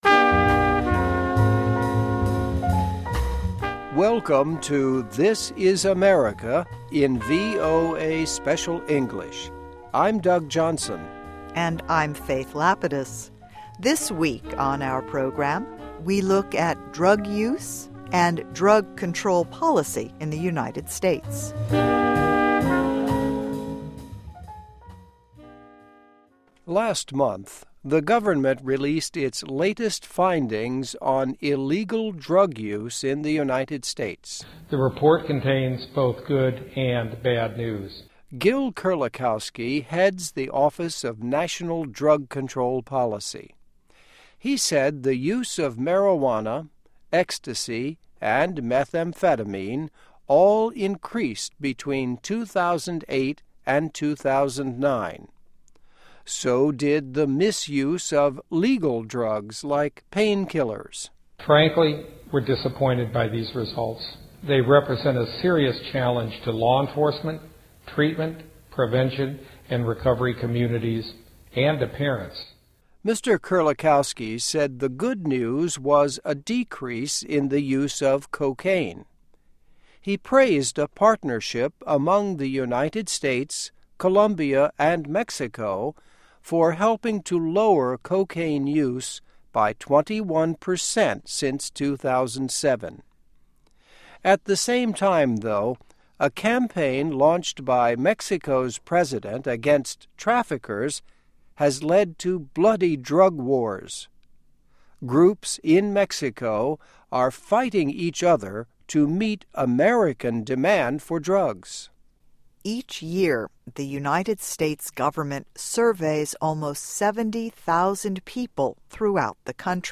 (MUSIC)